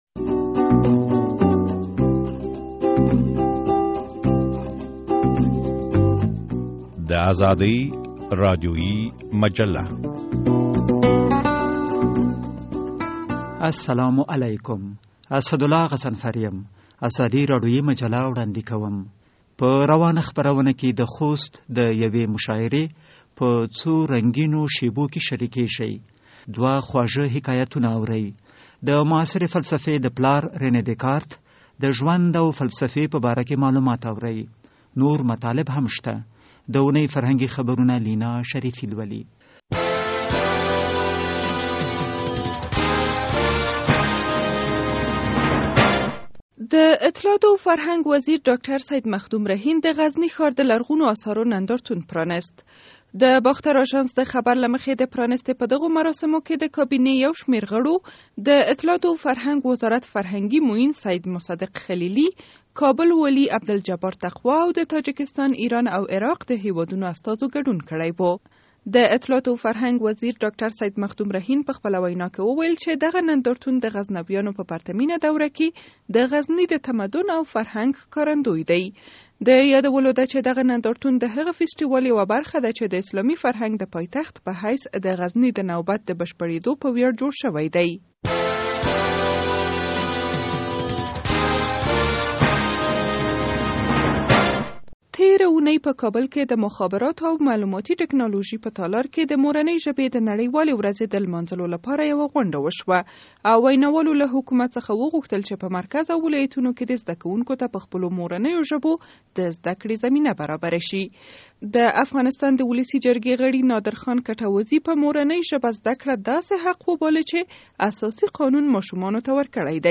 په دې راډیویي مجله کې د خوست د یوې مشاعرې په څو رنګینو شېبو کې شریکېږئ، دوه خواږه حکایتونه اورئ.